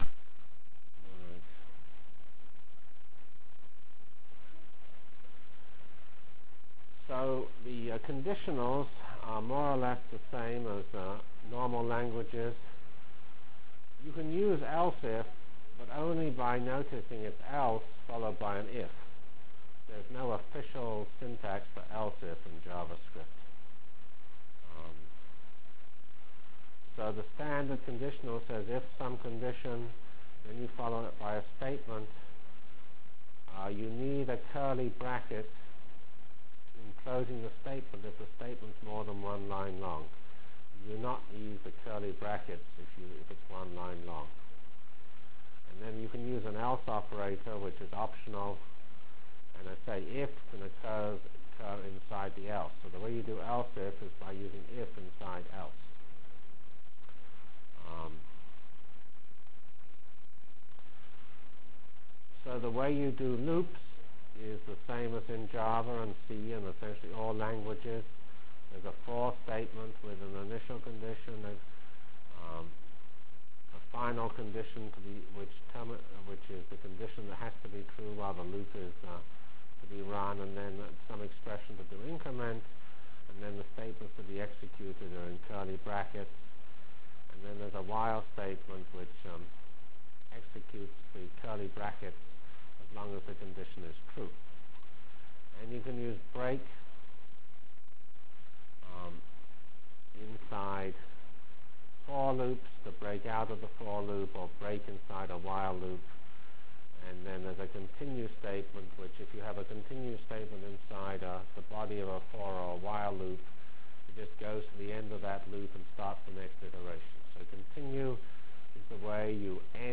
From Feb 17/19 Delivered Lecture for Course CPS616